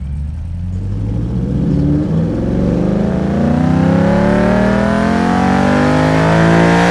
rr3-assets/files/.depot/audio/Vehicles/v8_04/v8_04_Accel.wav
v8_04_Accel.wav